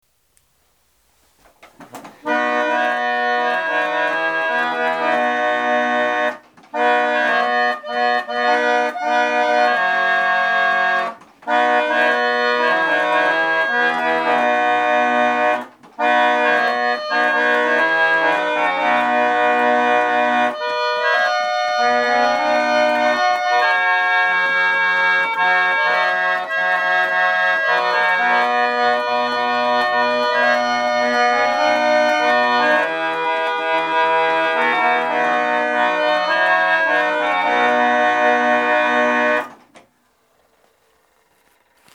So what does it sound like?
The rich full sound is produced as air is moved over the brass reed, causing it to vibrate.
Interestingly, as the pallet lever slides from pin to pin during a held note, the pallet wavers, producing a vibrato effect.